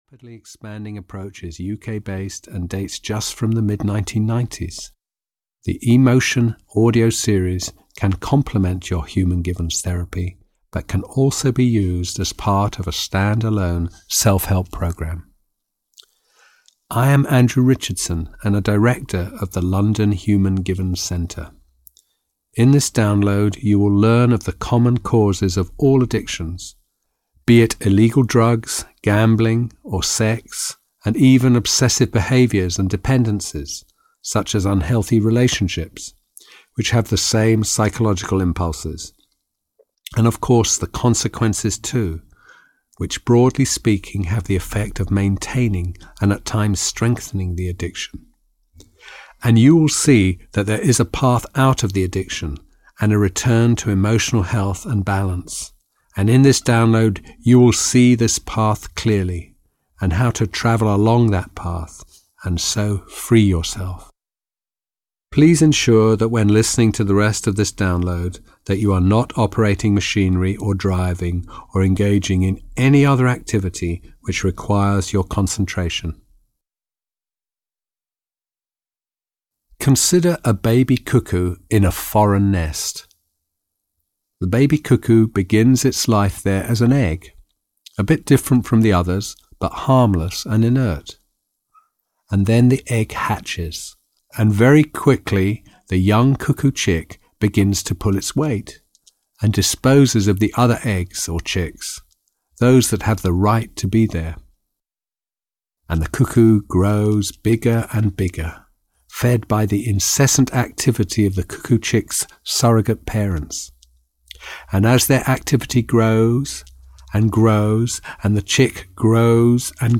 Stopping Addictions (EN) audiokniha
Ukázka z knihy